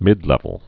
(mĭdlĕvəl)